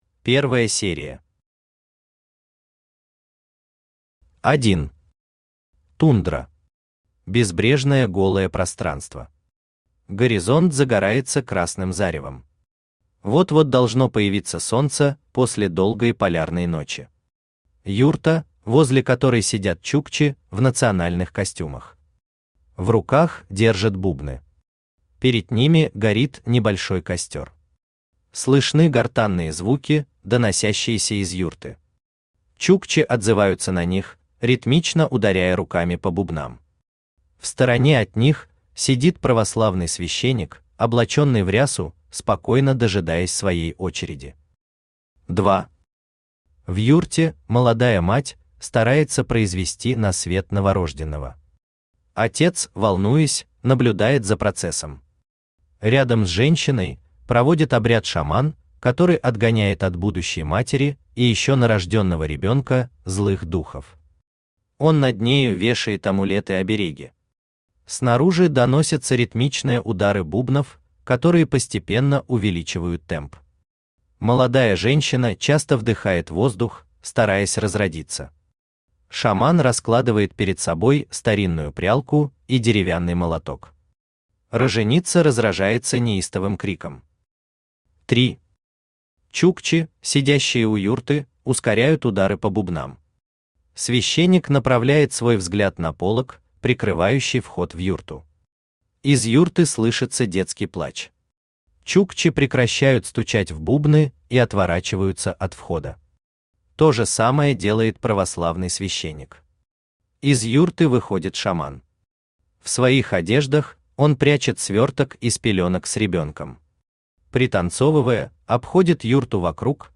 Аудиокнига Шаман по выходным | Библиотека аудиокниг
Aудиокнига Шаман по выходным Автор Сергей Алексеевич Глазков Читает аудиокнигу Авточтец ЛитРес.